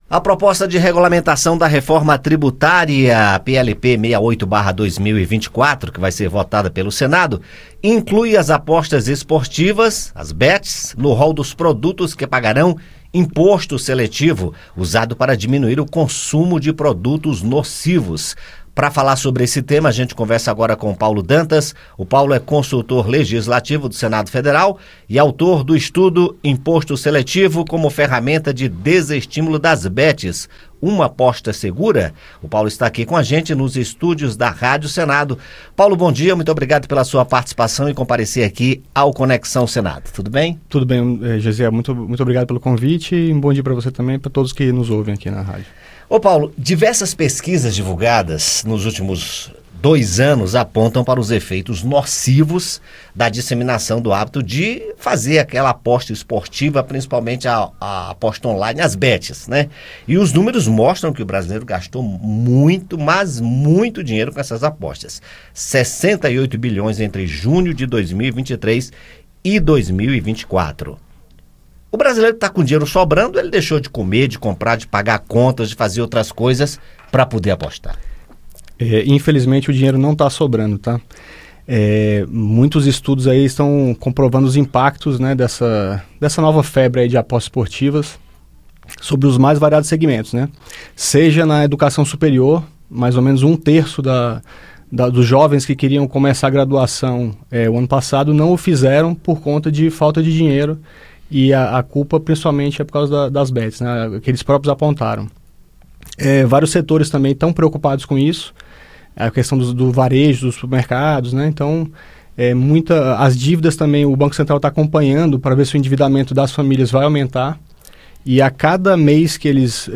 Em entrevista ao Conexão Senado